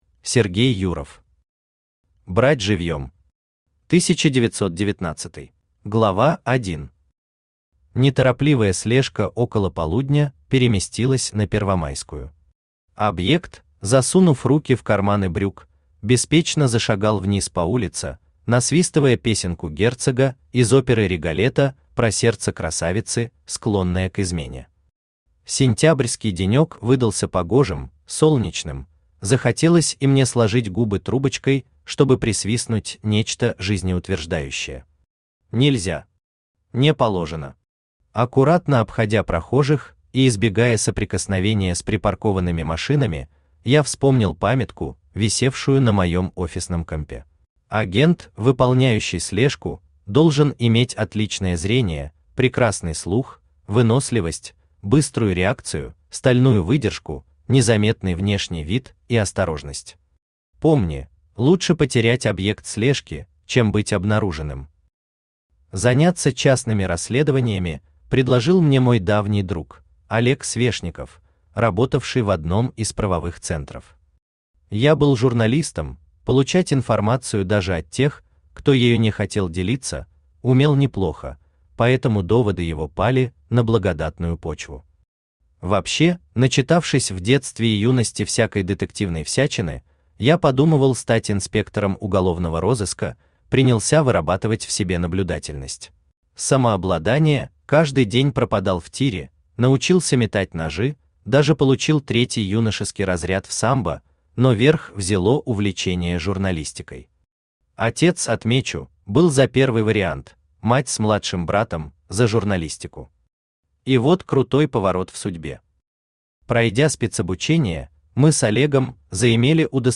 Аудиокнига Брать живьем! 1919-й | Библиотека аудиокниг
Aудиокнига Брать живьем! 1919-й Автор Сергей Дмитриевич Юров Читает аудиокнигу Авточтец ЛитРес.